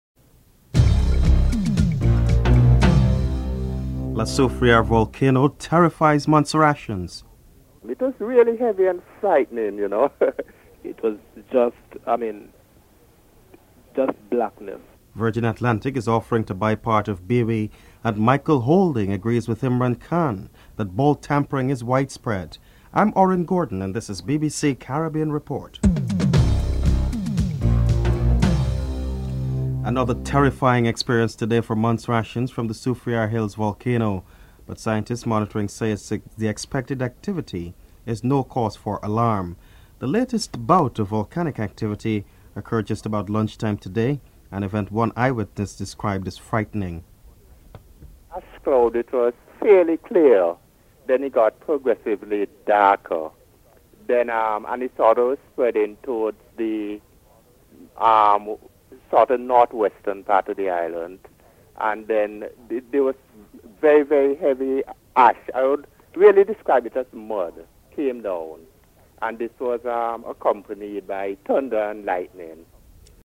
1. Headlines (00:00-00:28)